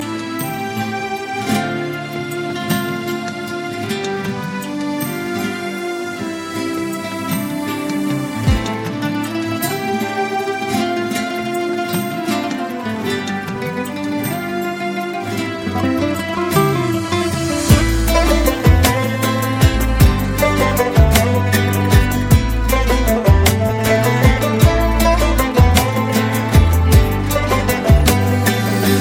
kategori : Classical